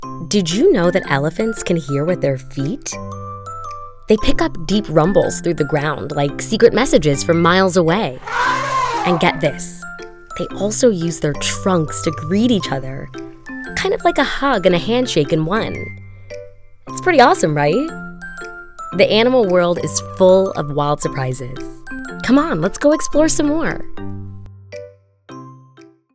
Warm, polished, and engaging voiceover talent with a background in theater & law
Explainer - Children's Learning, Kids, Science, Classroom, Education, Learning